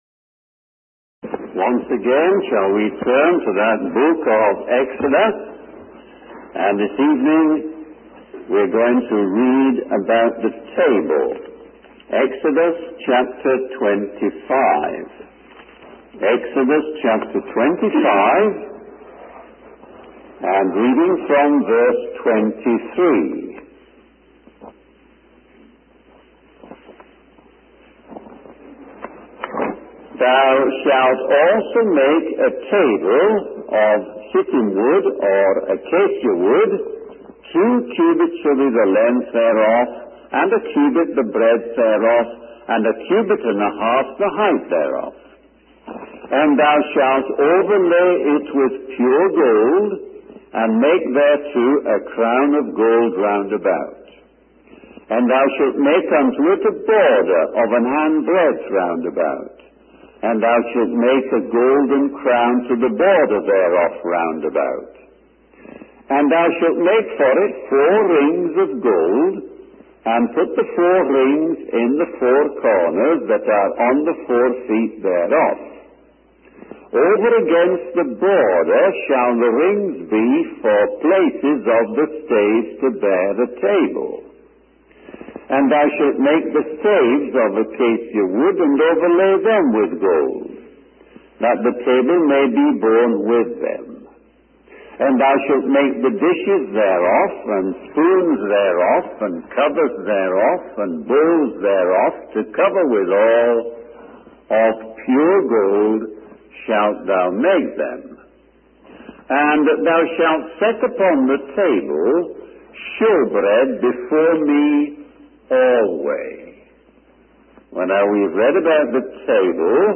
In this sermon, the speaker discusses the significance of the 'wheels within wheels' mentioned in the Bible.